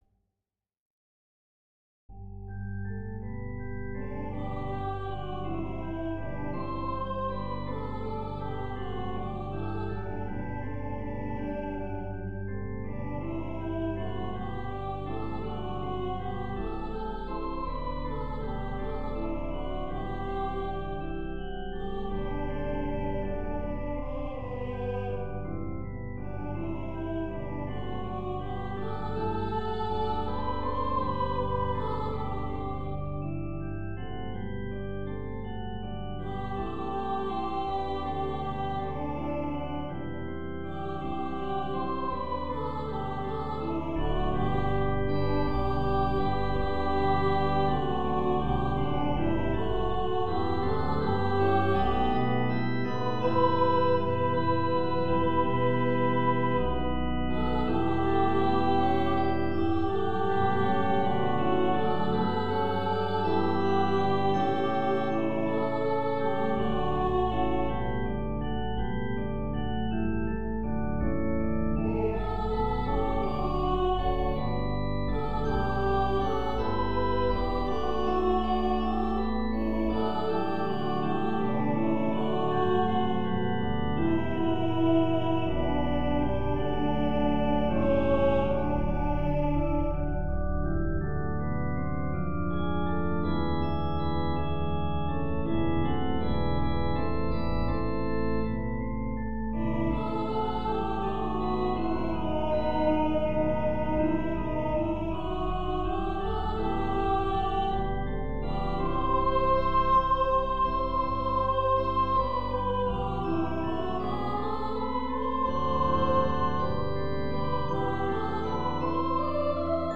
Score and Practice files for the singers:
Alto
howlovelyarethemessengers-alto.mp3